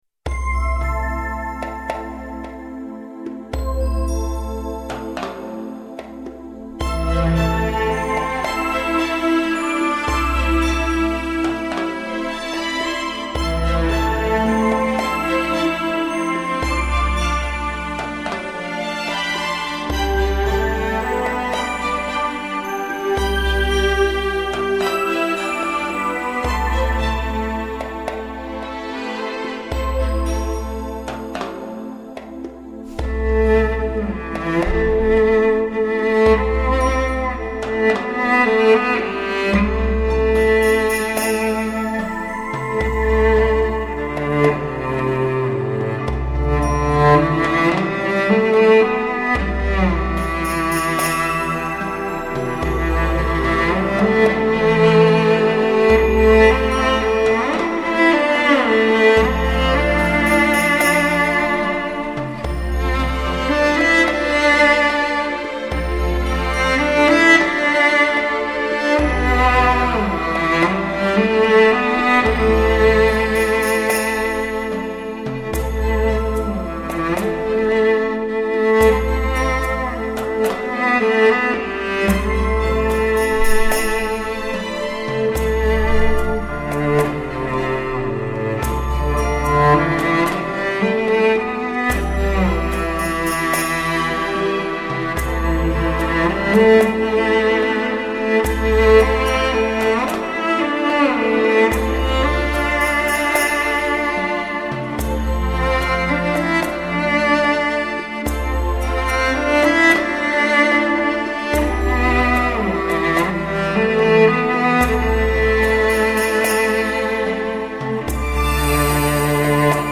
专辑格式：DTS-CD-5.1声道
淡淡松香弥漫，以弓弦作画的歌者，在尘封的记忆里，